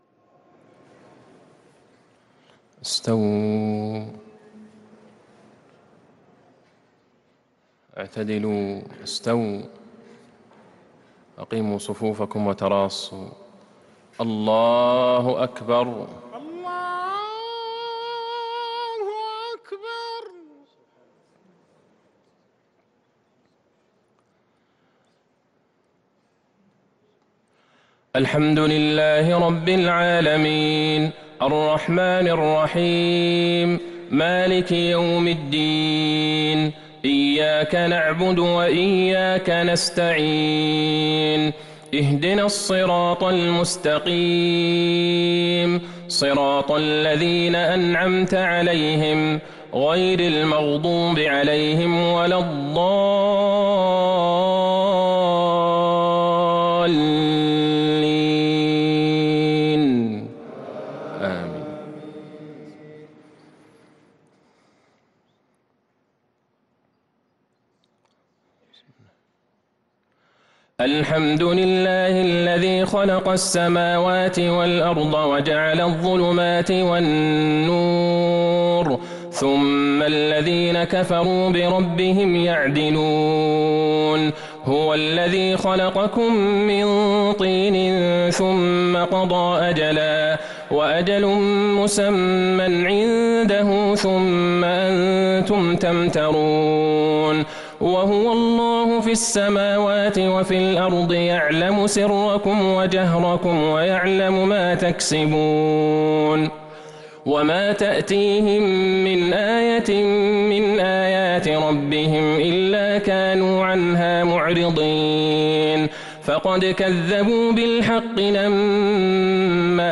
عشاء الأحد ٣ شعبان ١٤٤٣هـ | فواتح الأنعام ١ - ١٨ | Isha prayer from Surah Al-An’aam 6-3-2022 > 1443 🕌 > الفروض - تلاوات الحرمين